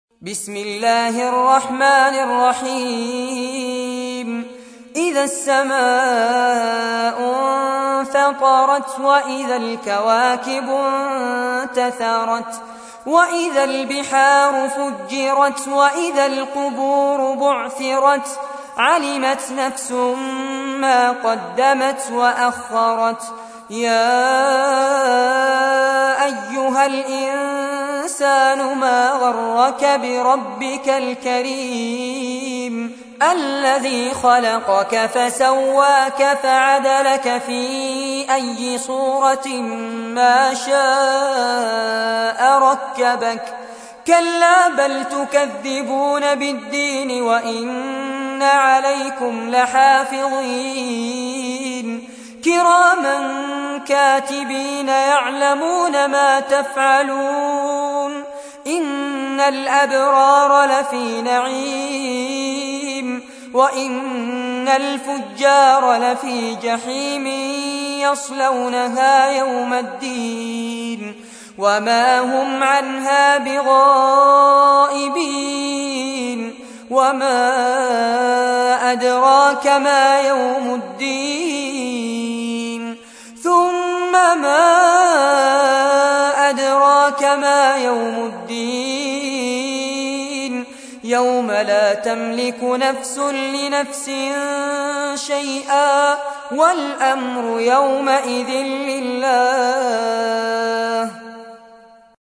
تحميل : 82. سورة الانفطار / القارئ فارس عباد / القرآن الكريم / موقع يا حسين